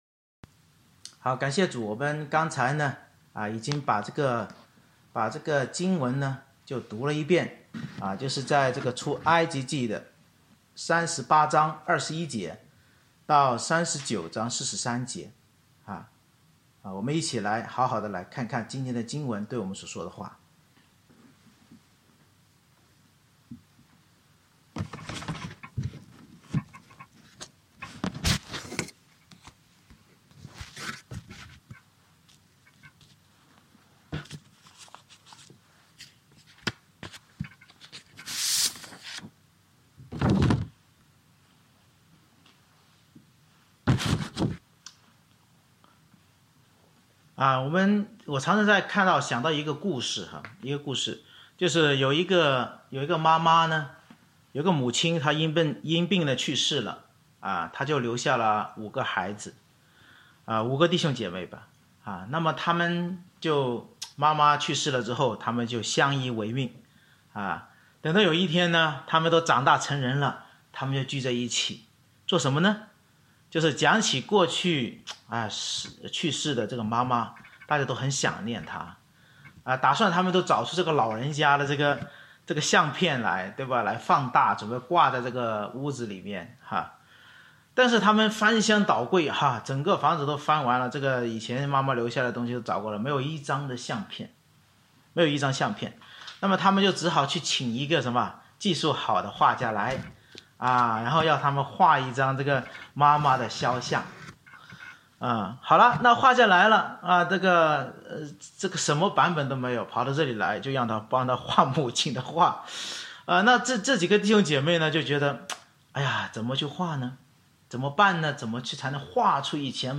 February 7, 2021 靠圣灵能力建造神的家－－归主为圣的君尊祭司 Series: 《出埃及记》讲道系列 Passage: 出埃及记38:21-39:43 Service Type: 主日崇拜 神将圣灵充满比撒列带领团队完成建造会幕的总价值并祭司圣衣制作，教导我们圣灵要建立一群效法基督并穿戴归主为圣的君尊祭司来服事教会的门徒。